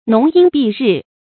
浓荫蔽日 nóng yīn bì rì 成语解释 形容树木枝繁叶茂，可以遮住阳光。